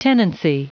Prononciation du mot tenancy en anglais (fichier audio)
Prononciation du mot : tenancy